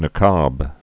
(nə-käb)